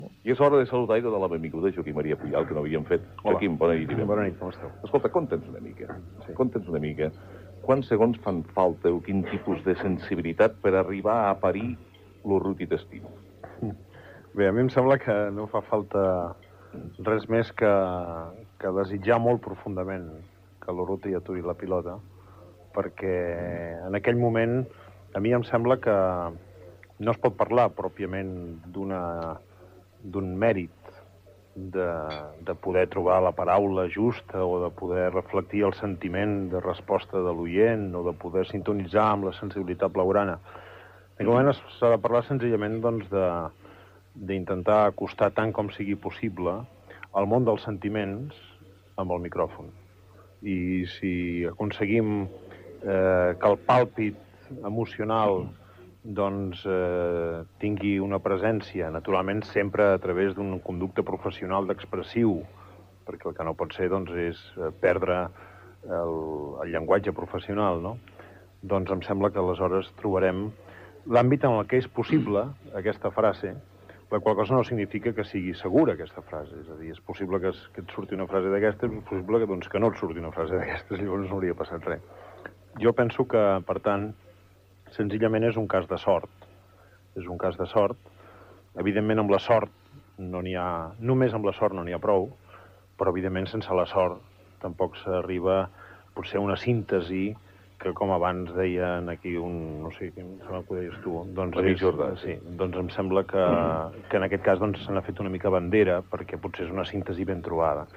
Entrevista a Joaquím Maria Puyal, amb motiu de les 500 transmissions de Futbol en català,. Explica com va sorgir l'expressió "Urruti t'estimo"